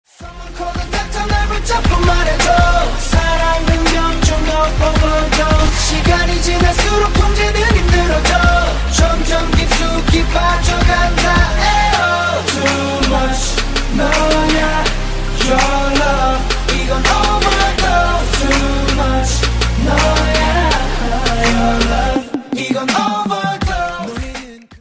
dance
K-Pop